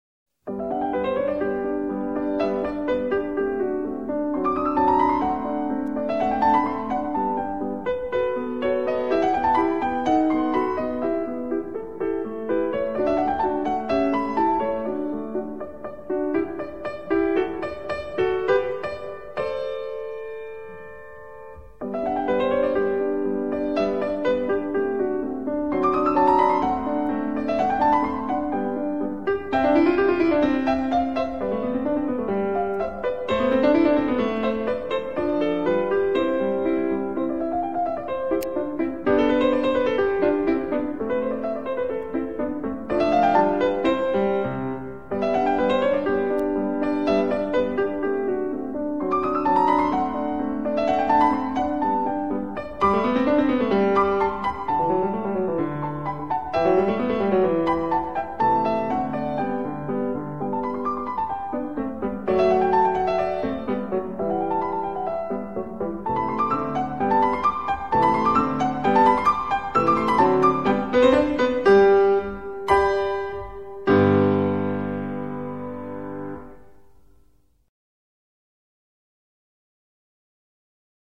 0225-钢琴名曲木偶.mp3